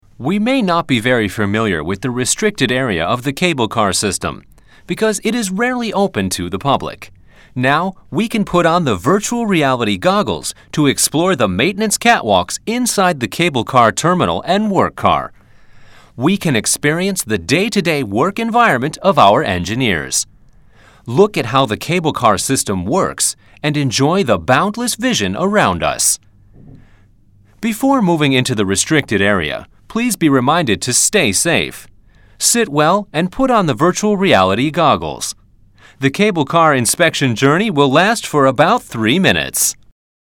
Cable Car Discovery Centre Audio Guide (English)